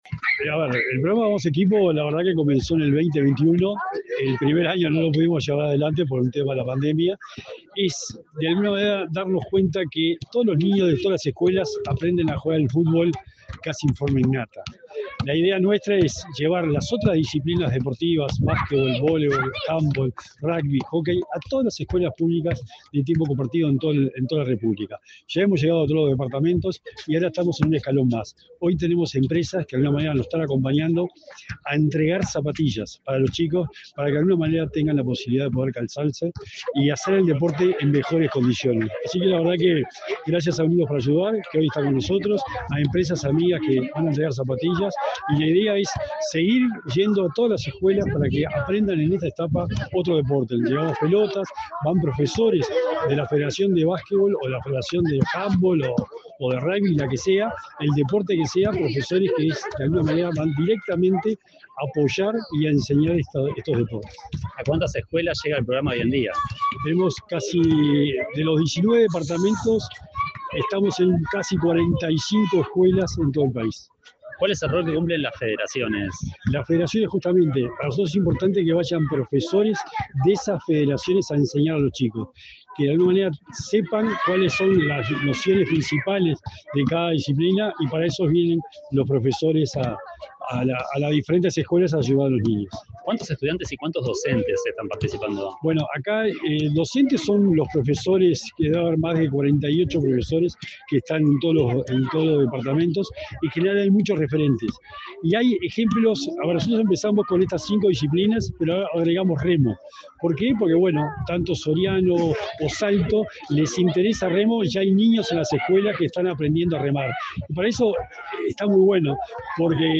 Entrevista al secretario nacional del Deporte, Sebastián Bauzá
El secretario nacional del Deporte, Sebastián Bauzá, dialogó con Comunicación Presidencial, antes de presentar el programa "Vamo equipo", en la